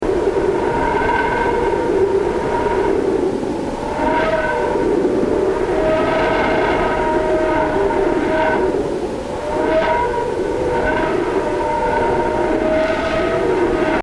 A wind of fast moving particles blows out from our Sun, and although space transmits sound poorly, particle impact and variable-field data from NASA's near-Sun Parker Solar Probe is being translated into sound.
psp_whistler_mode_waves_1.mp3